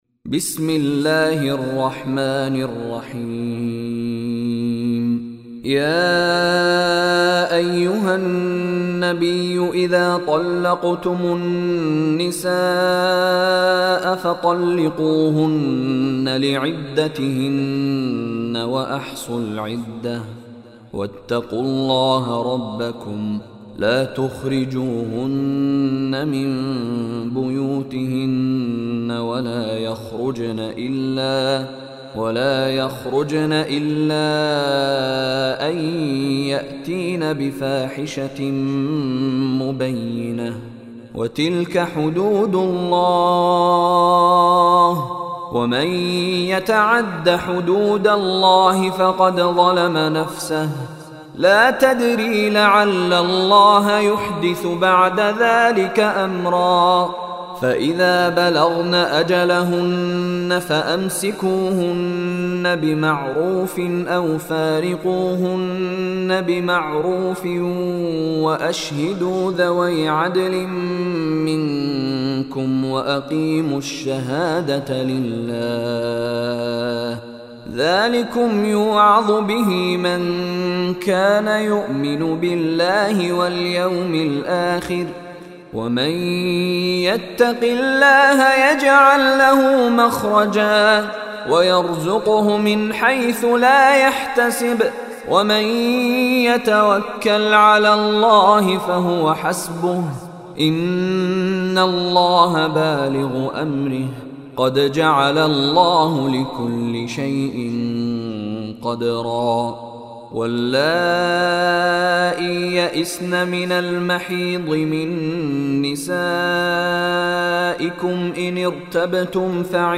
Surah At Talaq Recitation by Mishary Rashid
Surah At-Talaq is 56th chapter/ surah of Holy Quran. Listen online or download mp3 tilawat / recitation of Surah At-Talaq in the beautiful voice of Sheikh Mishary Rashid Alafasy.